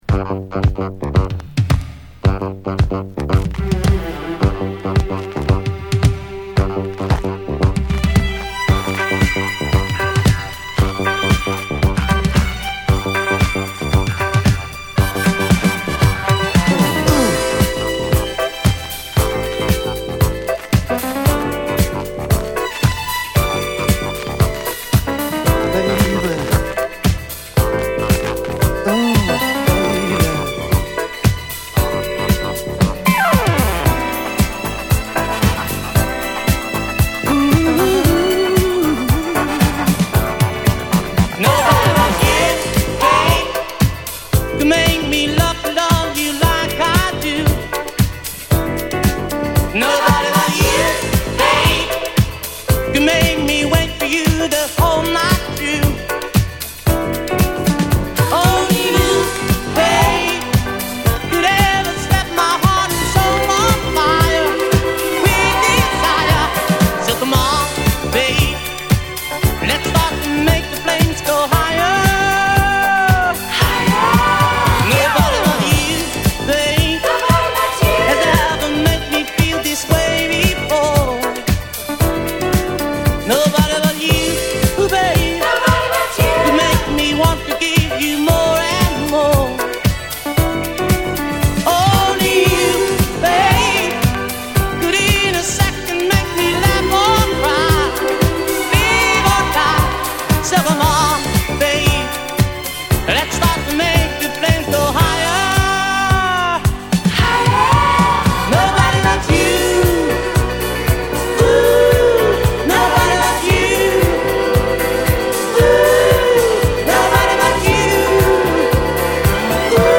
＊B1頭にチリノイズ有り。